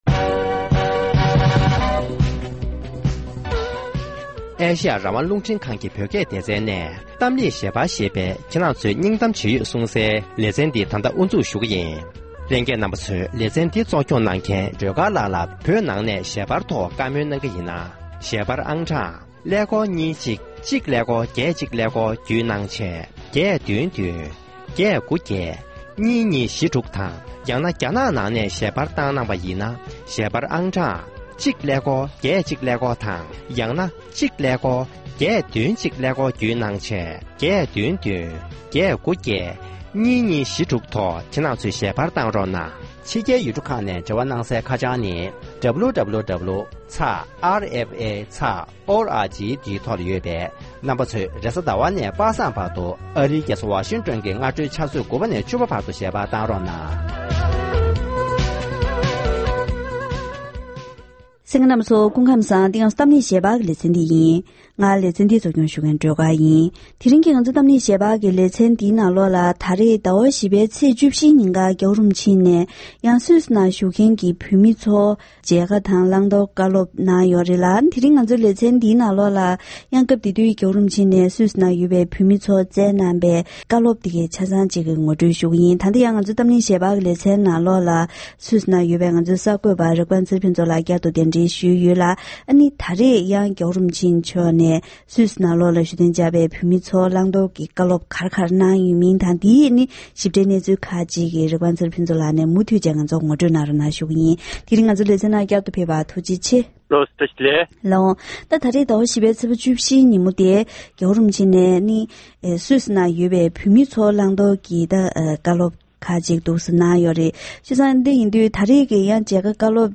སུད་སི་དང་ཡོ་རོབ་ཁུལ་གྱི་བོད་མི་རྣམས་ལ་བོད་ཀྱི་རིག་གཞུང་ལ་དོ་སྣང་རྒྱུན་འཛིན་དགོས་པའི་བཀའ་སློབ་ཕེབས་པ།